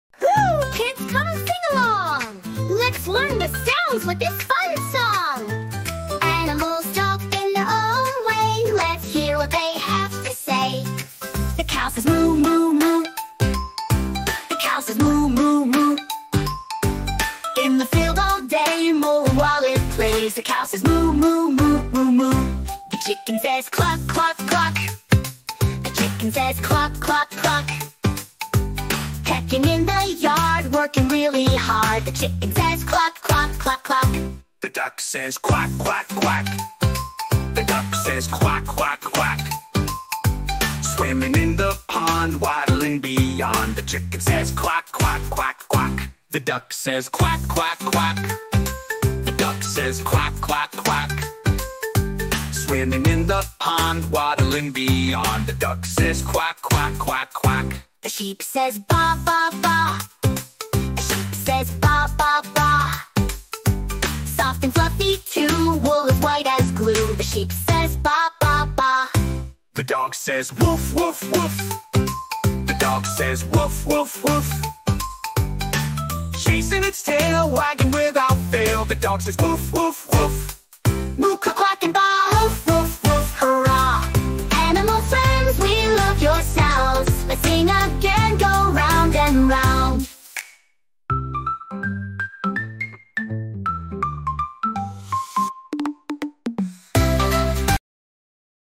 Learn Animal Noises with Fun & Music!